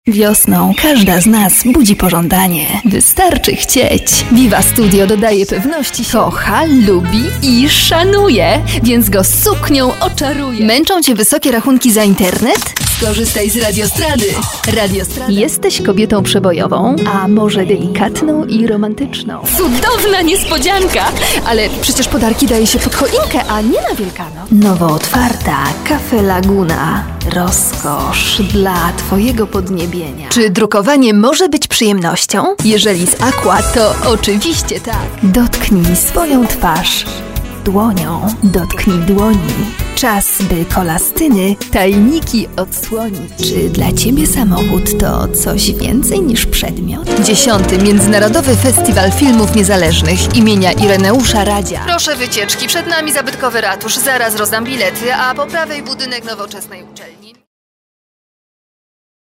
Sprecherin polnisch.
Sprechprobe: Werbung (Muttersprache):
polish female voice over artist